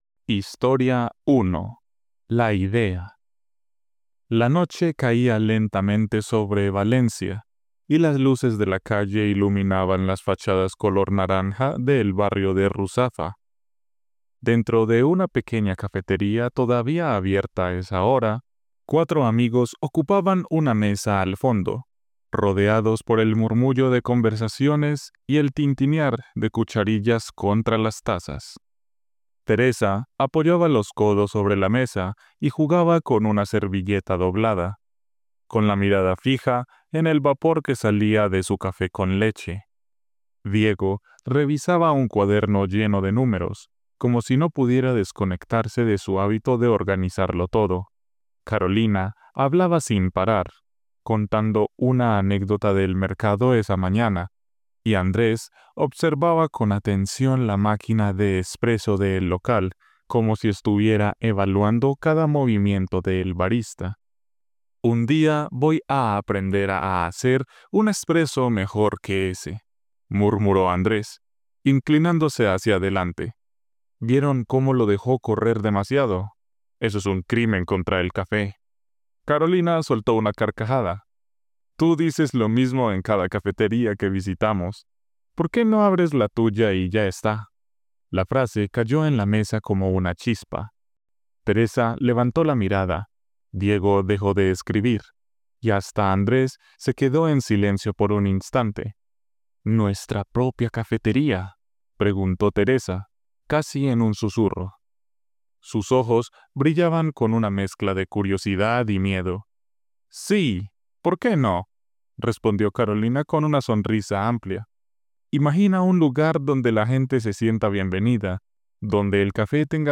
• Native-Spanish Audio
Immerse yourself in an excerpt from Startup Café—a lively 8-story series with native-Spanish narration that brings every moment to life.